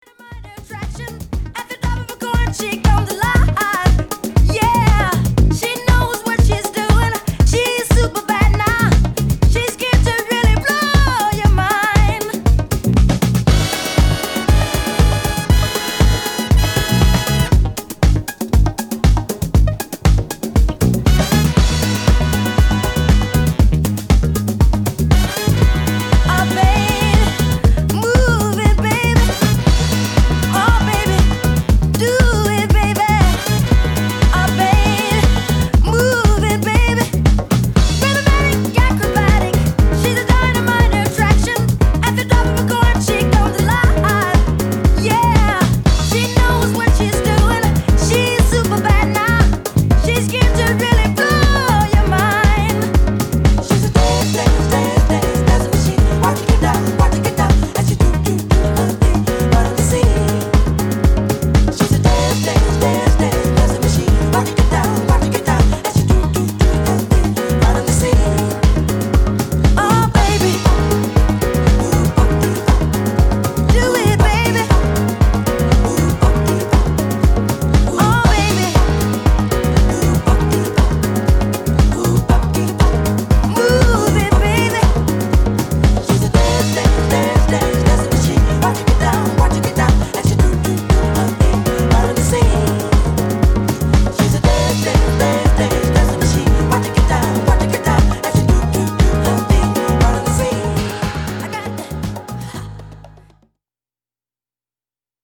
House / Techno
いい塩梅にフロアを彩るテンション。